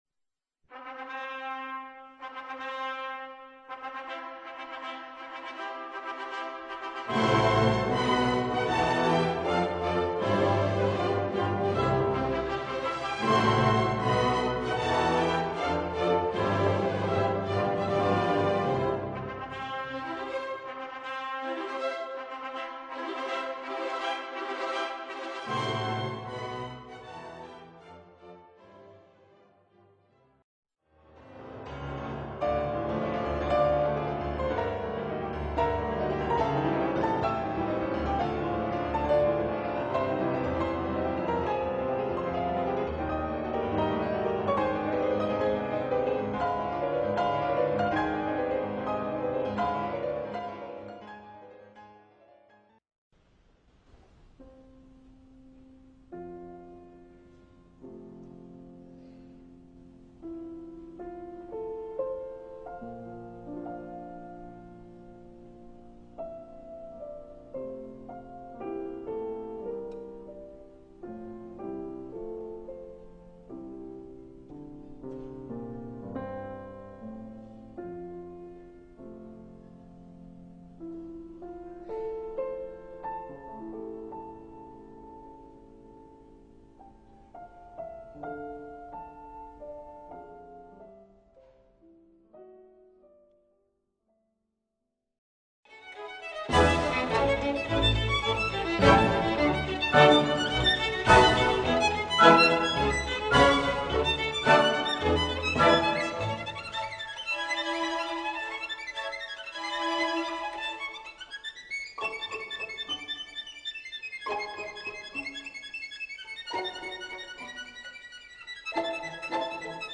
Ascolto selettivo